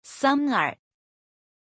Aussprache von Sumner
pronunciation-sumner-zh.mp3